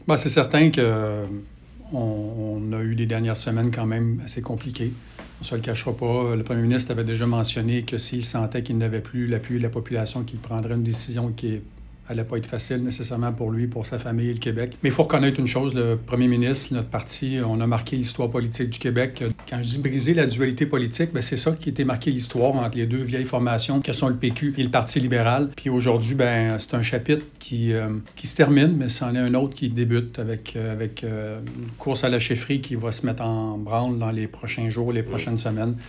En entrevue avec le service de nouvelles de M105, il est revenu sur les dernières semaines, qu’il décrit comme « compliquées ».